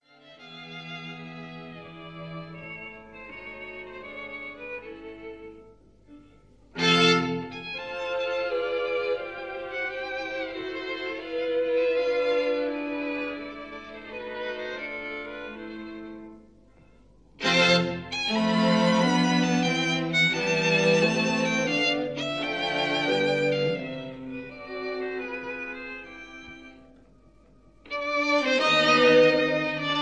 This is a 1950 recording of the Andantino